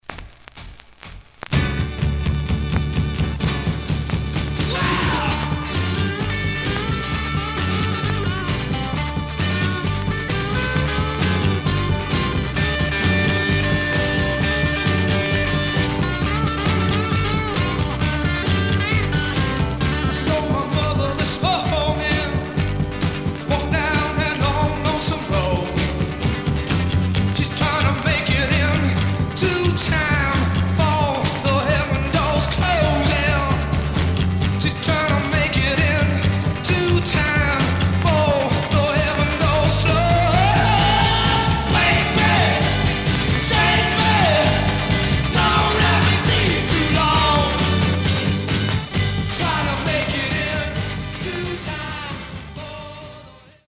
Some surface noise/wear
Mono
Garage, 60's Punk ..........👈🏼 Condition